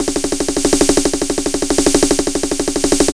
81 SN FILL-L.wav